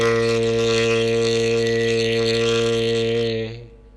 Vocale în format .wav - Vorbitorul #6